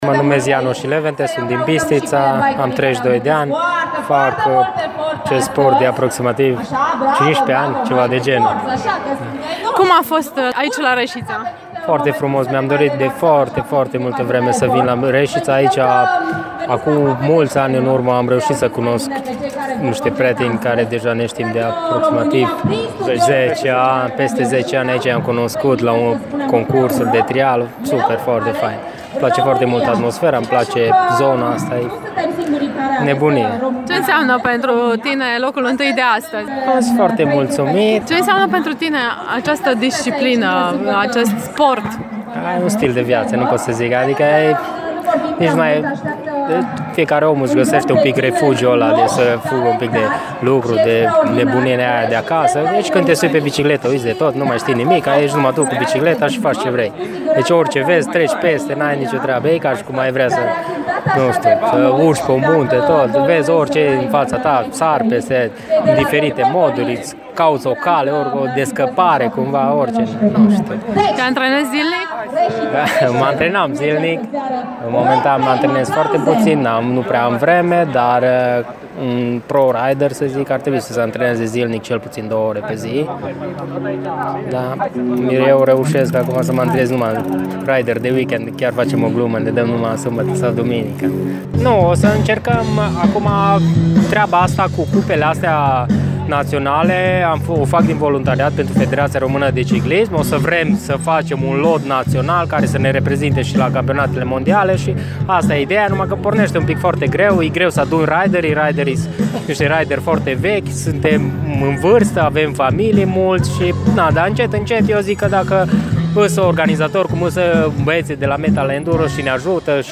La final de concurs am dialogat cu învingătorii: